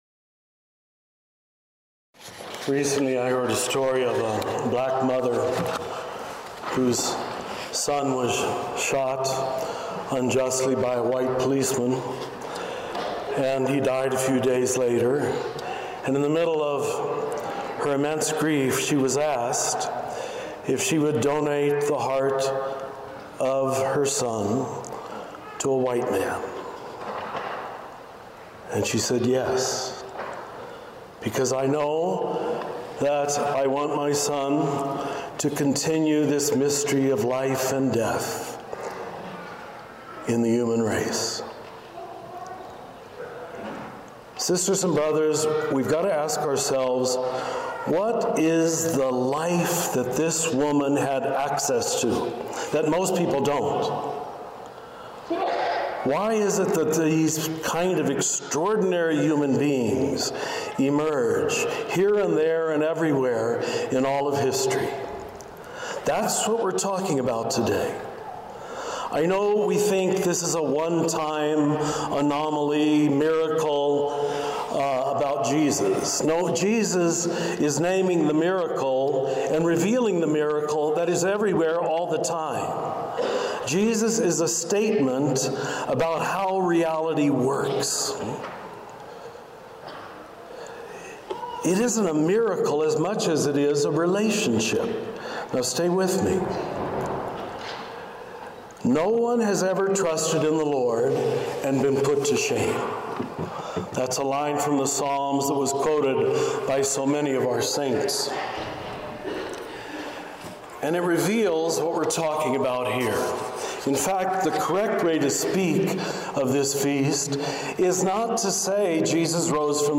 Easter Homily: Reality Moves Toward Resurrection